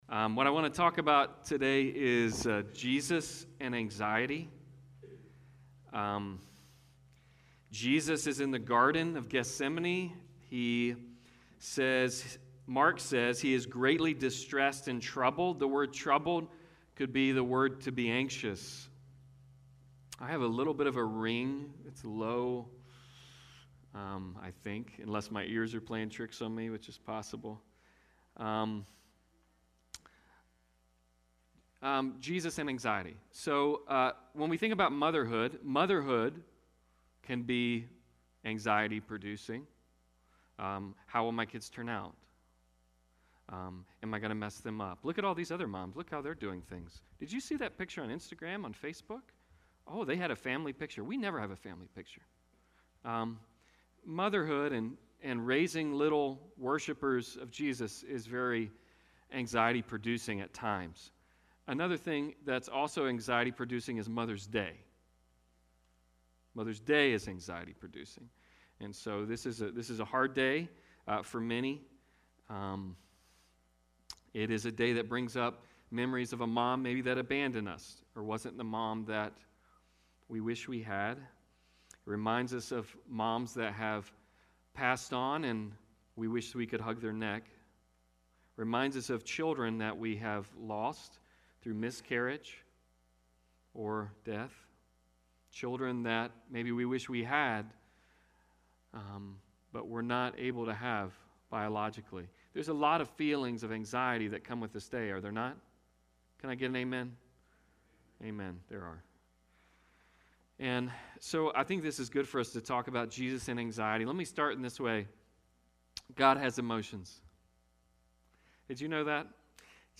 Passage: Mark 14:26-42 Service Type: Sunday Service